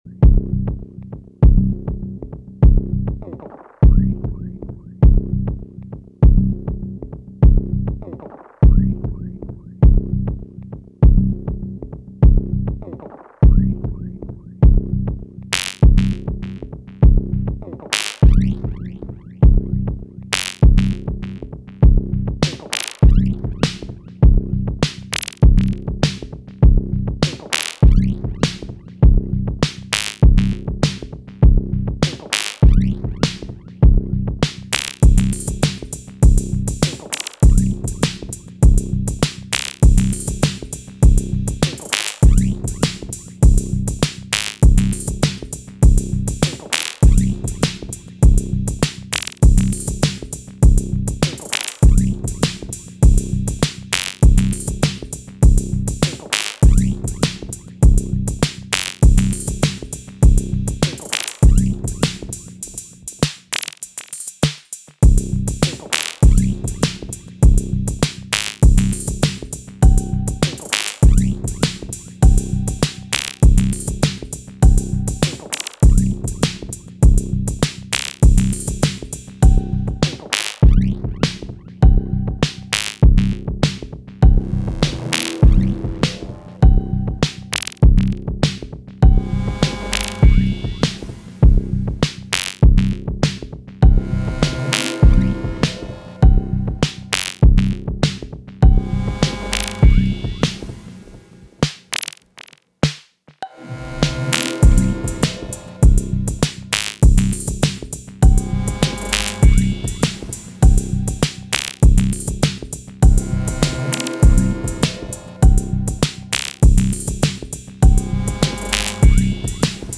Es fehlt an Druck und an Brillanz.
Fokus auf den analogen Instrumenten.
Kann sein das der Mix n bisschen sucked.